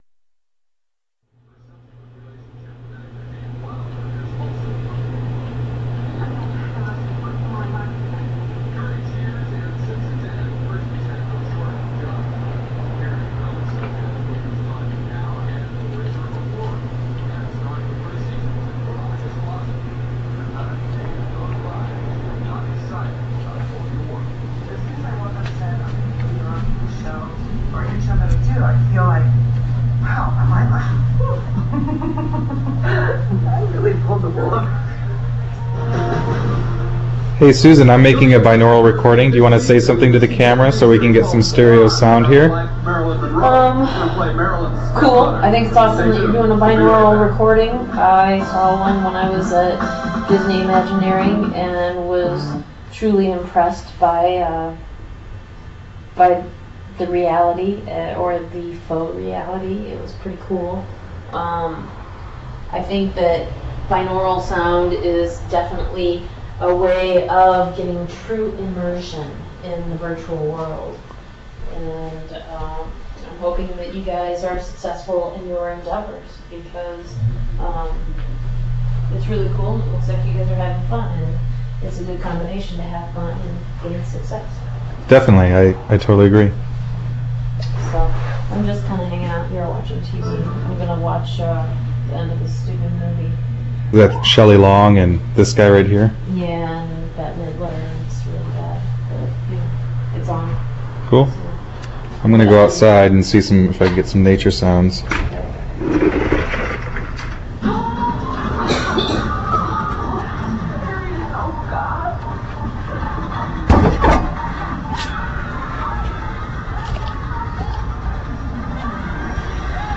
View Video from our first Quarter Prototypes and listen to a sampling of our first binaural audio recording:
Binaural Test: (you must use headphones)
binaural-200k.rm